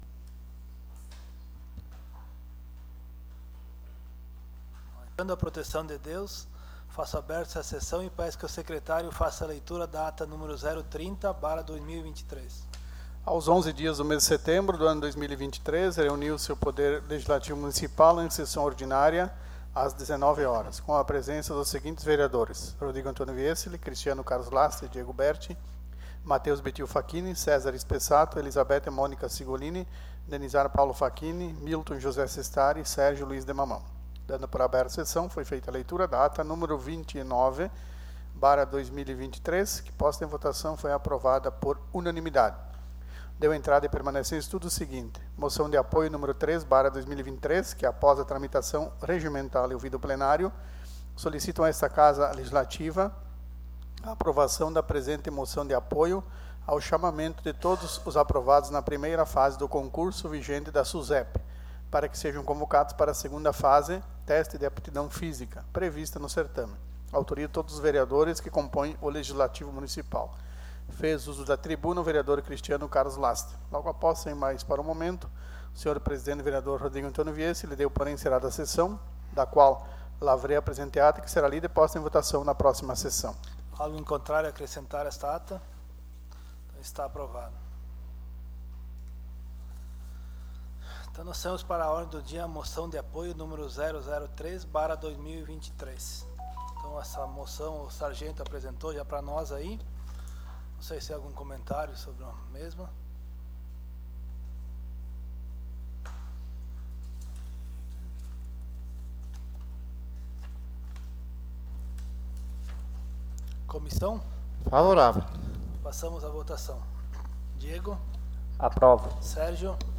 Sessão Ordinária - 18/09/2023